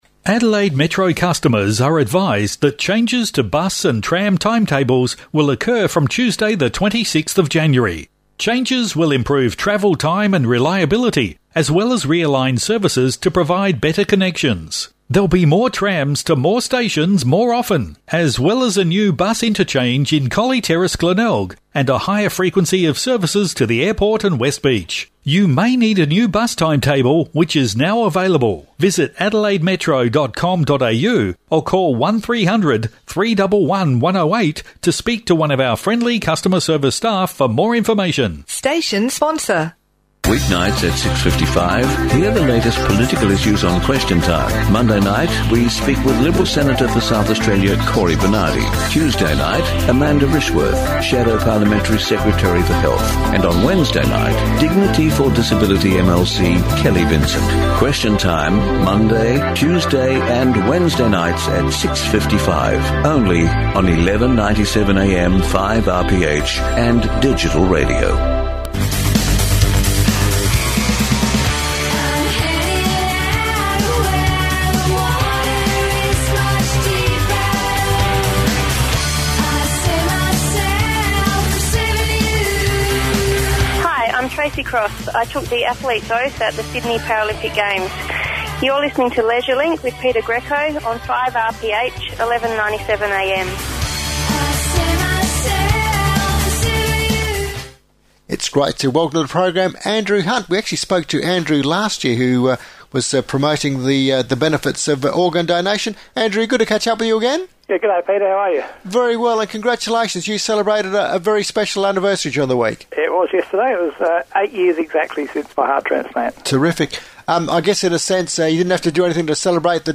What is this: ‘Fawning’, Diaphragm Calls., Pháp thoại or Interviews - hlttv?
Interviews - hlttv